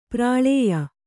♪ prāḷēya